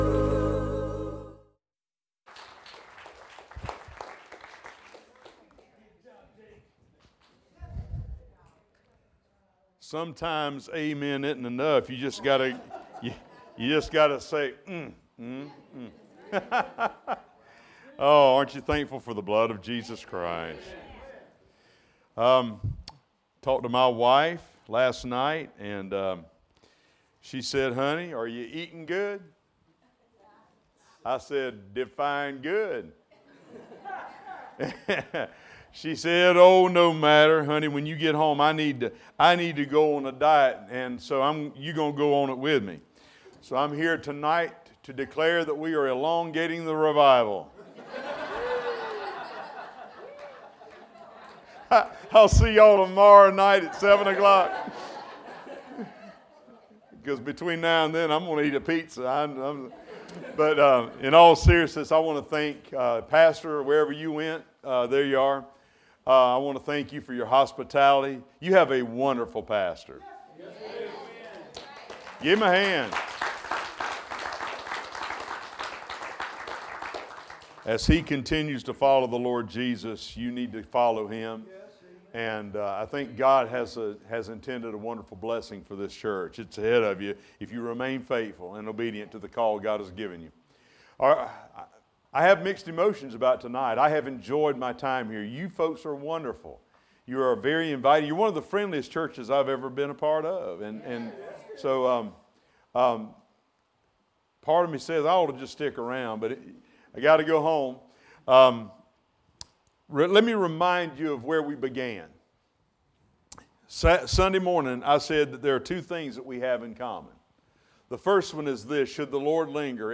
Spring Revival – Wednesday Evening